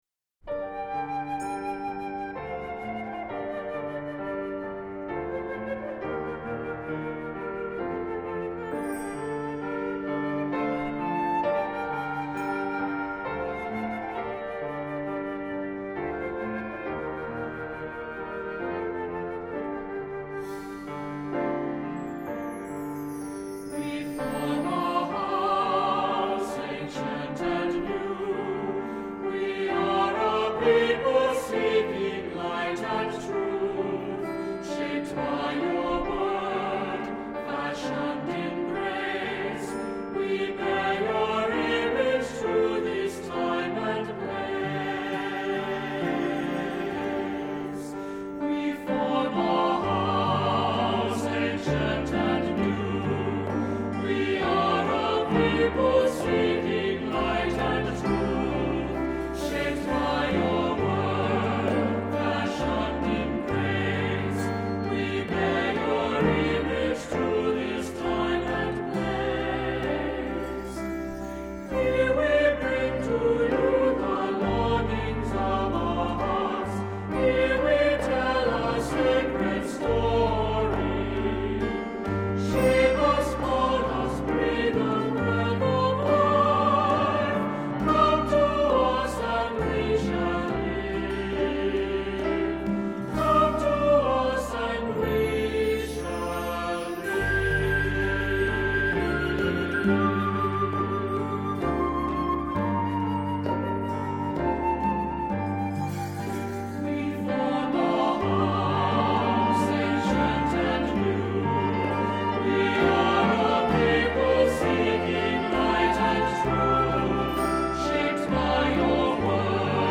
Accompaniment:      Keyboard, C Instrument I;C Instrument II
Music Category:      Christian
C instrument I and II parts are optional.